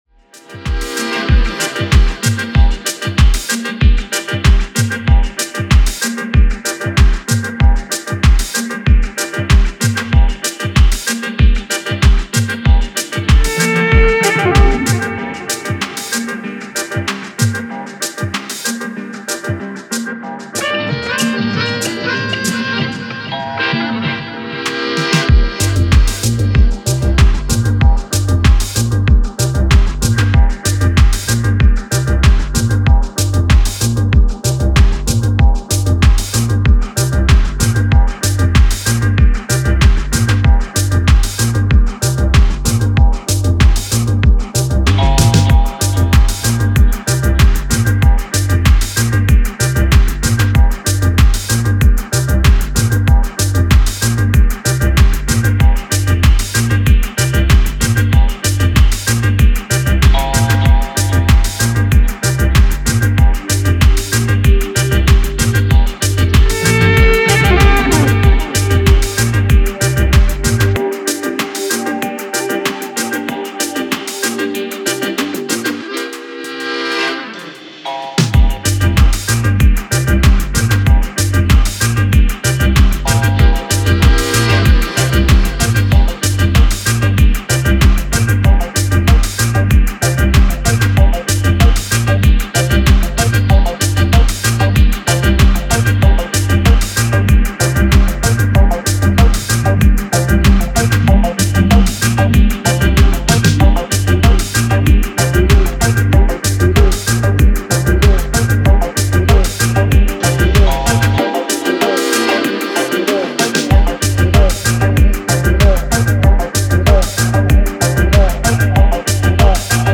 オリエンタリズムが充満したアフロ/コズミック方面の音好きにもおすすめしたいスローモー・ディスコ